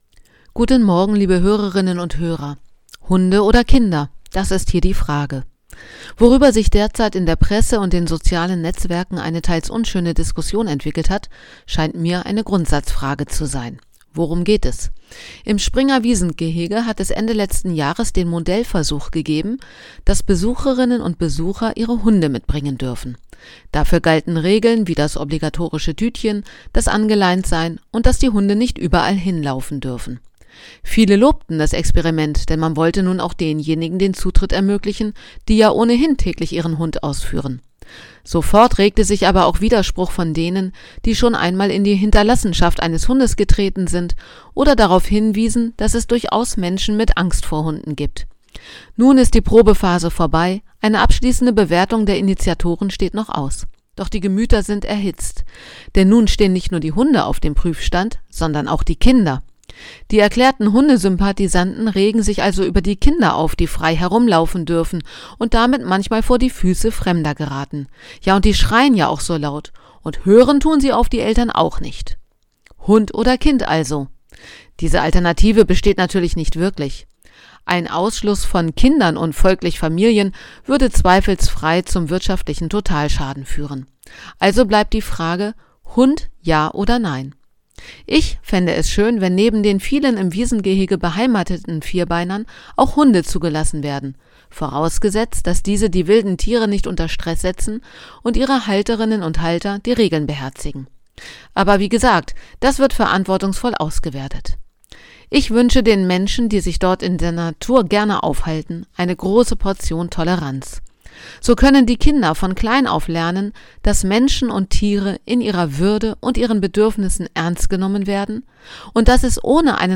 Radioandacht vom 10. Januar